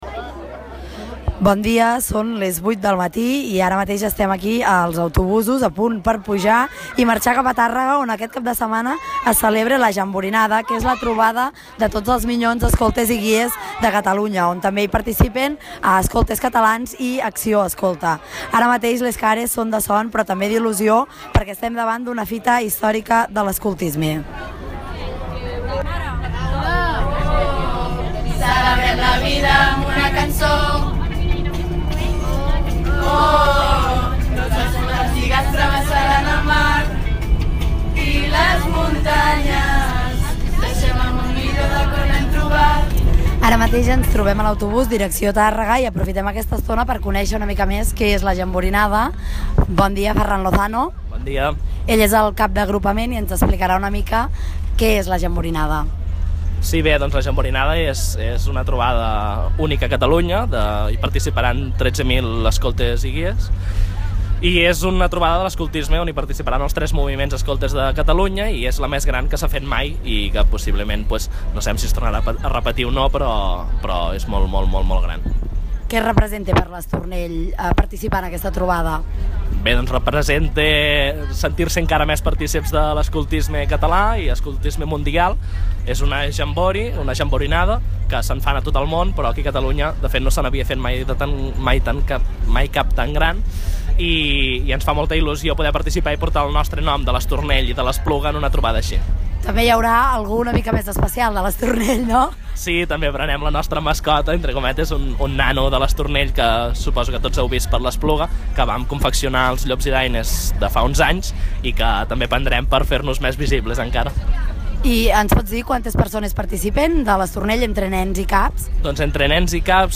Reportatge-Jamborinada.mp3